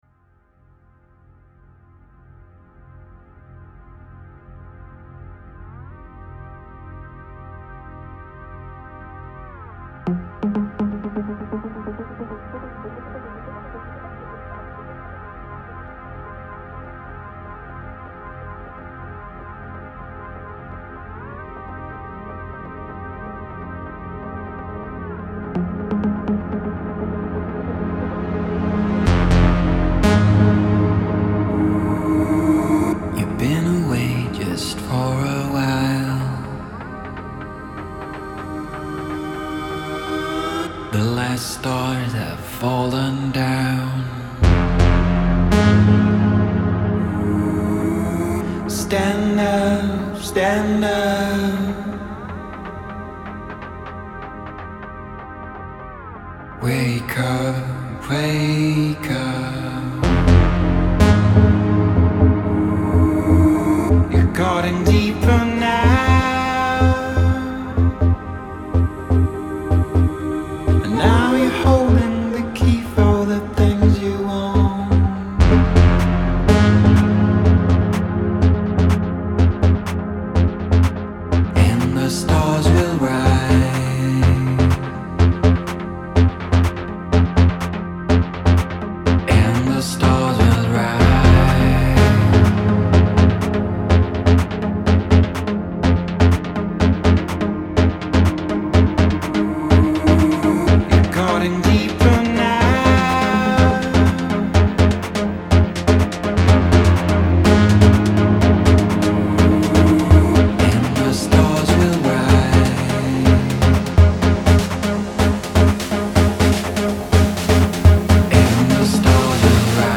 Melodic Techno